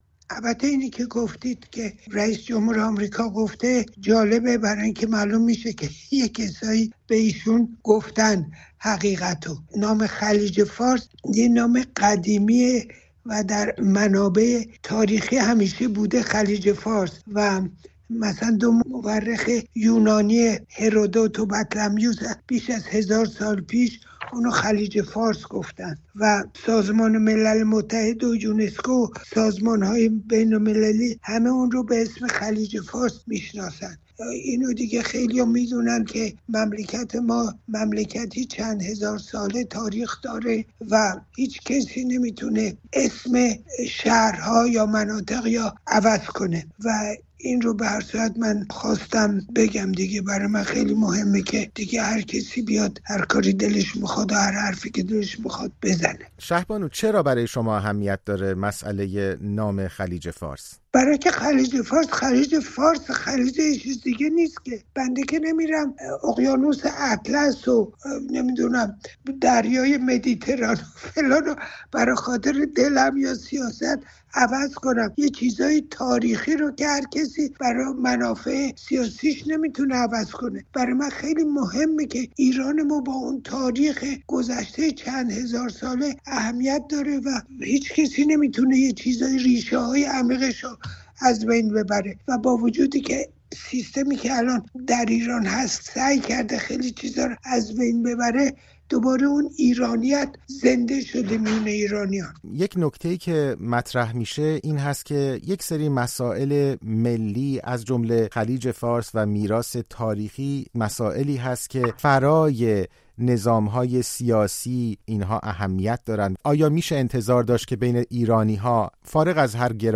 شهبانو فرح پهلوی در گفت‌وگو با رادیوفردا به این خبر واکنش نشان داده است.